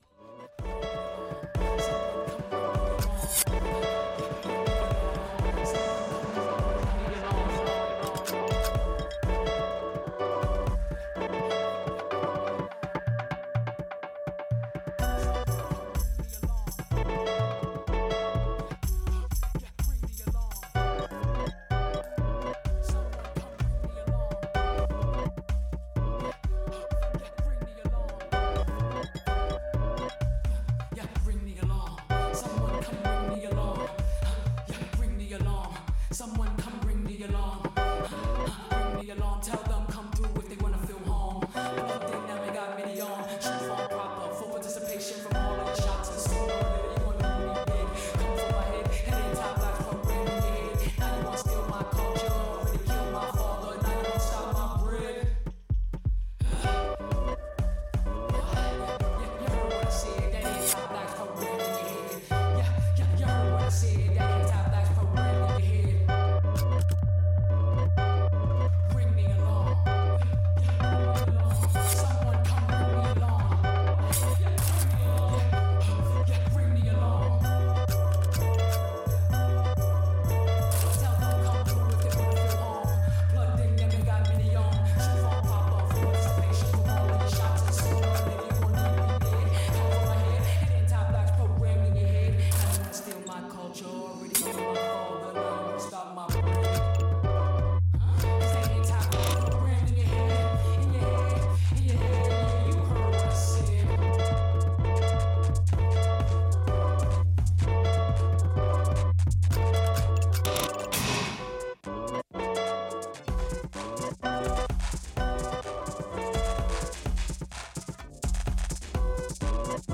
Electronic Hip Hop